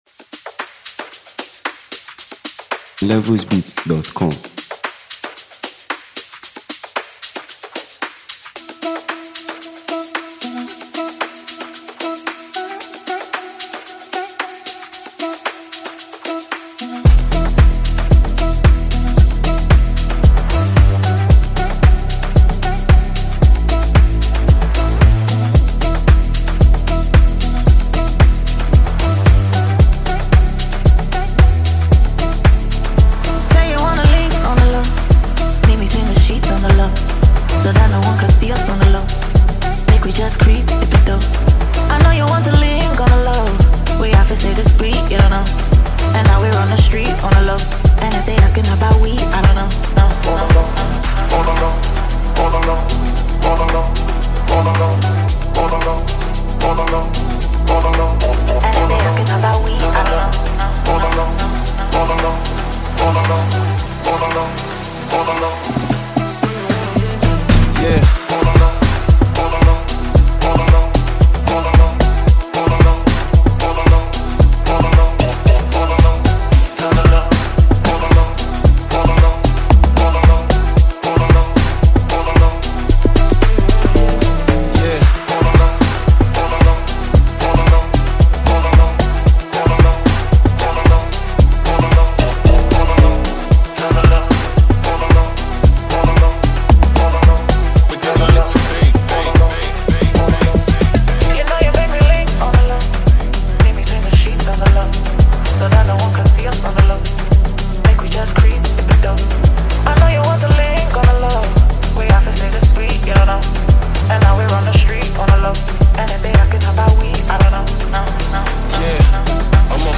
remix
the internationally recognized British rapper